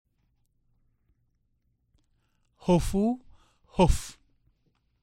1. Vowel contrasts
E.  Listen to the difference between a and ä.